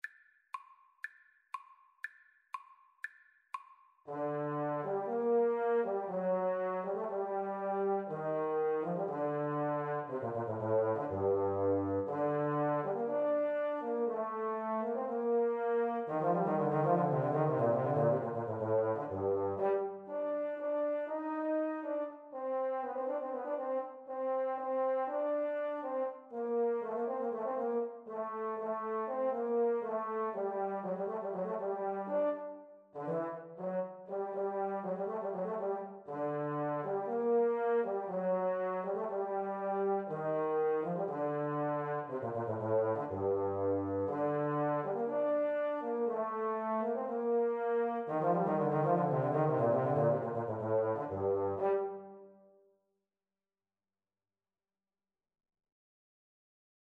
Free Sheet music for Tuba Duet
This piece comes from a set of 21 lively dance tunes based mostly on Hungarian themes.
2/4 (View more 2/4 Music)
G minor (Sounding Pitch) (View more G minor Music for Tuba Duet )
Allegro (View more music marked Allegro)
Tuba Duet  (View more Intermediate Tuba Duet Music)
Classical (View more Classical Tuba Duet Music)